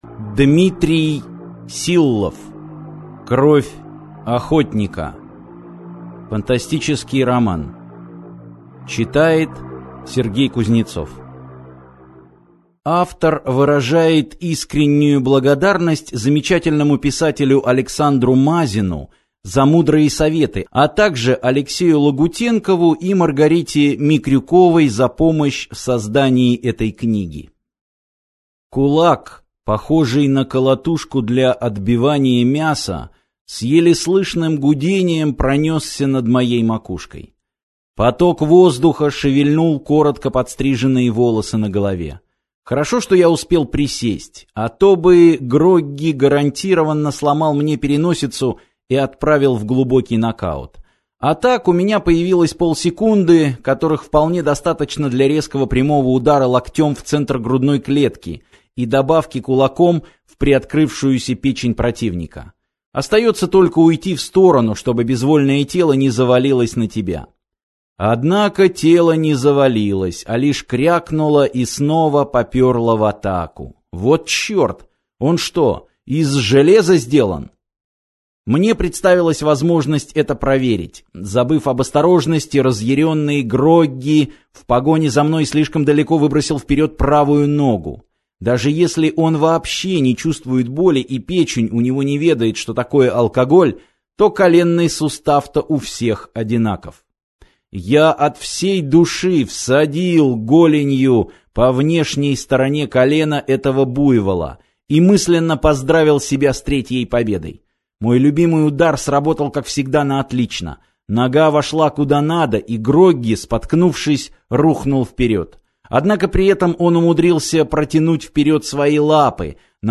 Аудиокнига Кровь Охотника | Библиотека аудиокниг